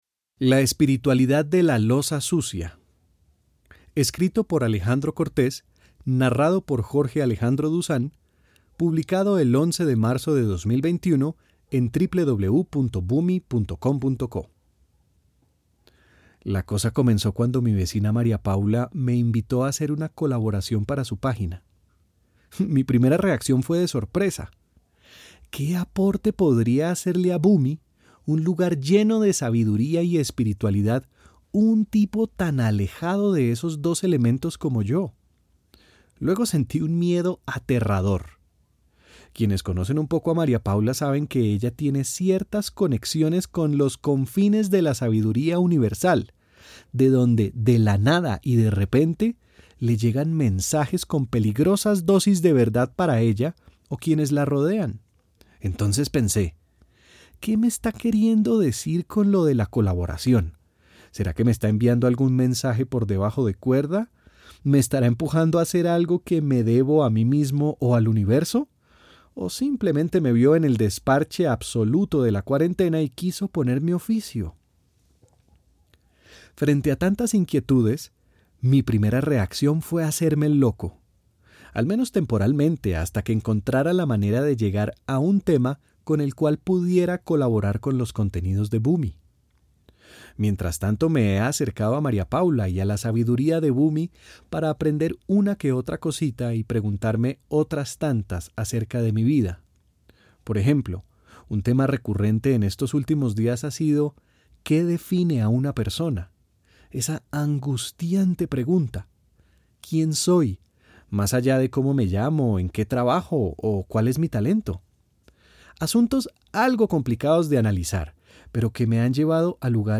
nos cuenta de manera jocosa